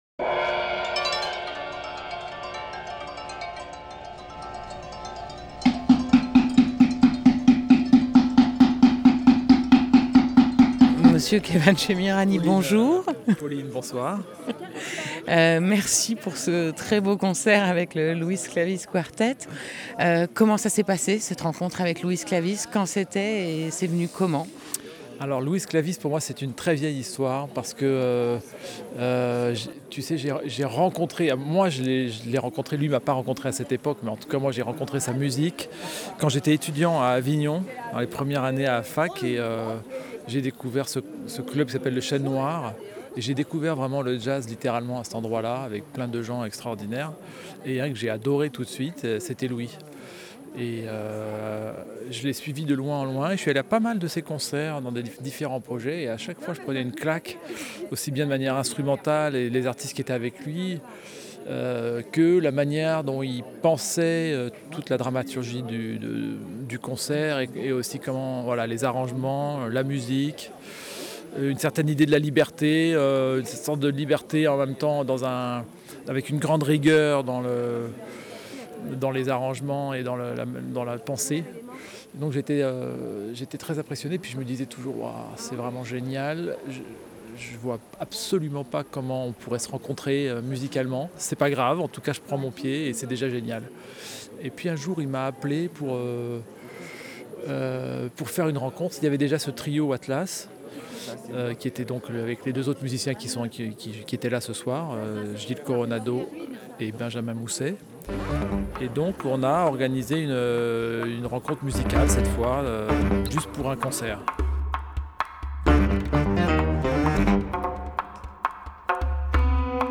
24 juillet 2016 19:44 | Interview